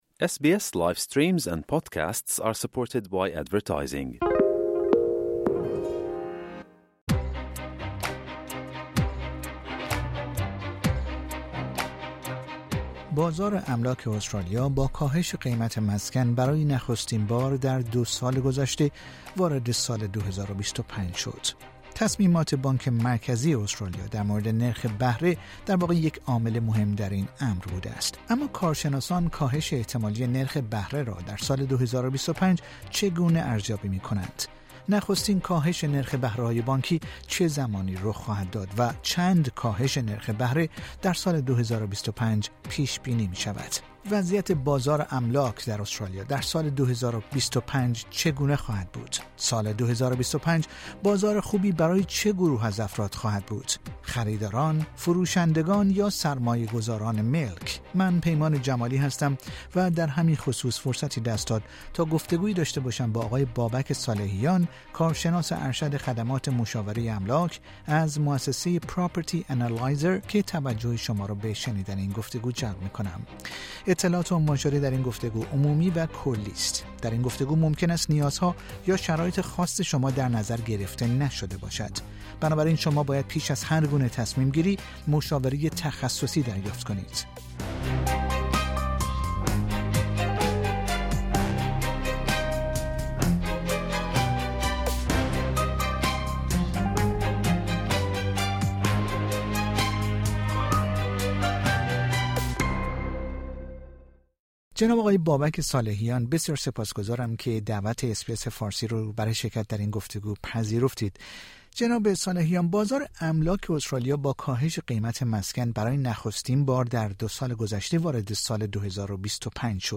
اطلاعات عنوان شده در این گفتگو، عمومی و کلی است.